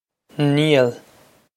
Niall Nee-ul
Pronunciation for how to say
This is an approximate phonetic pronunciation of the phrase.